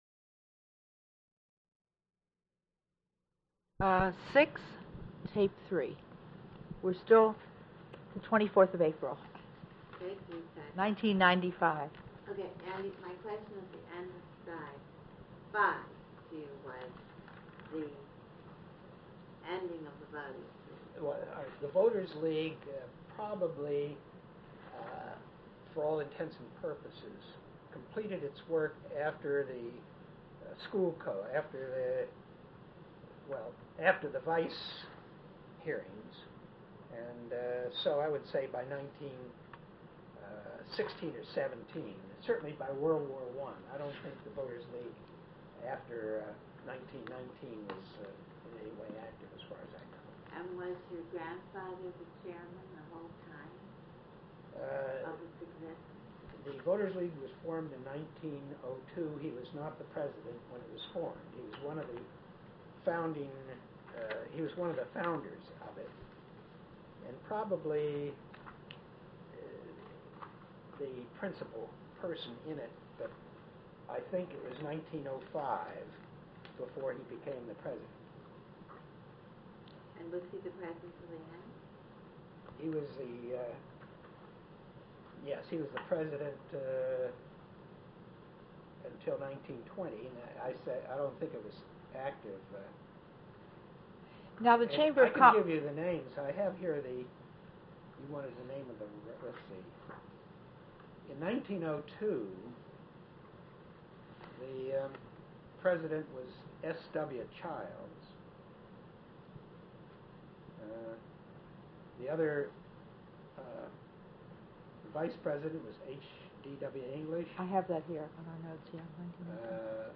Respondent. provides oral history of grandfather